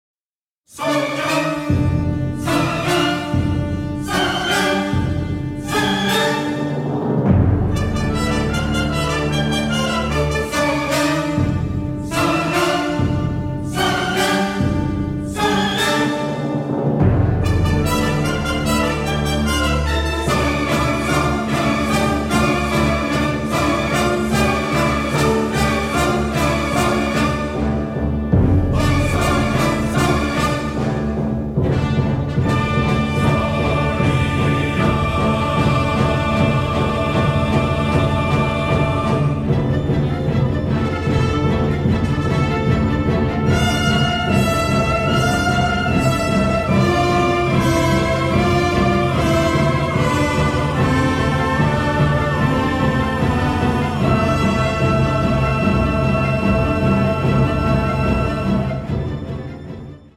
ALBUM STEREO TRACKS